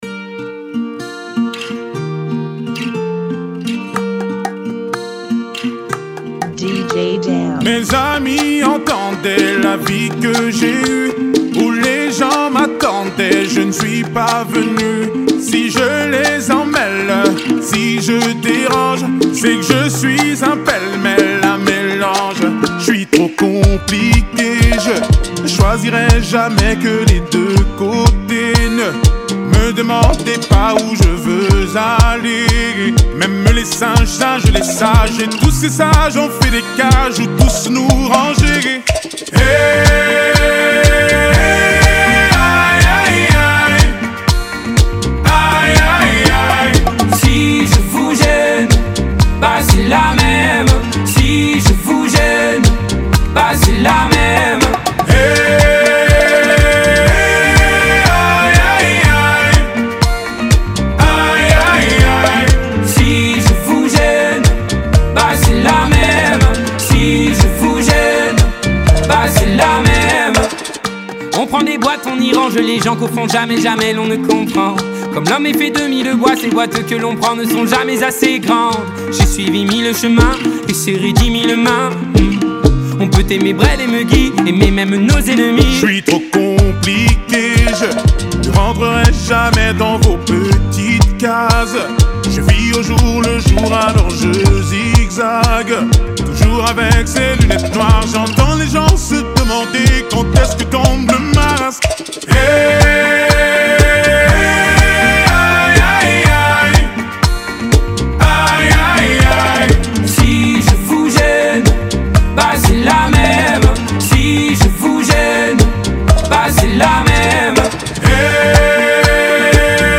Genre: Bachata Remix